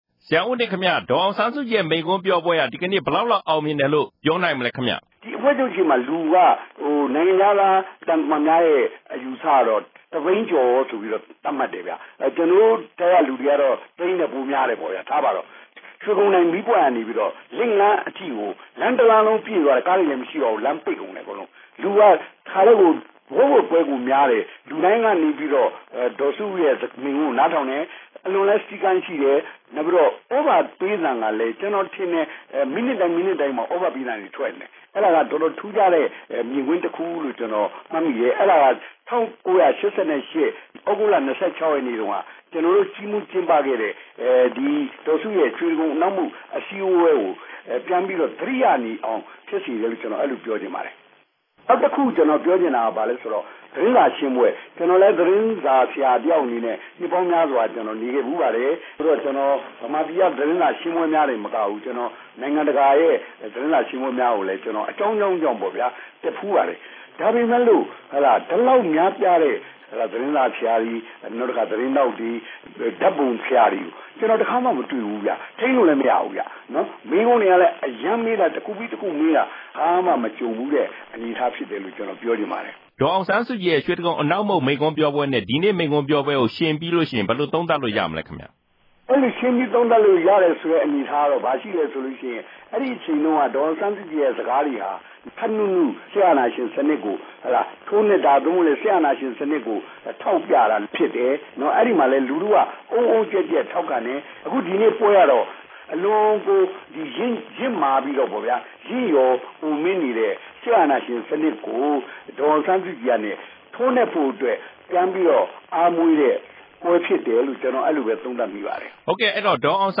ဒေါ်အောင်ဆန်းစုကြည် ပထမဆုံး မိန့်ခွန်း လူ ၁ သိန်းခန့် တက်ရောက်နားထောင်